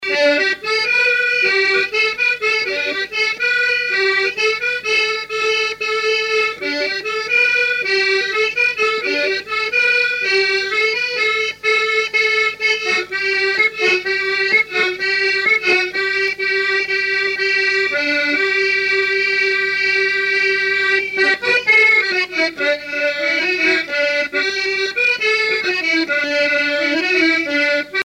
Mémoires et Patrimoines vivants - RaddO est une base de données d'archives iconographiques et sonores.
danse : ronde : grand'danse
musique à danser à l'accordéon diatonique
Pièce musicale inédite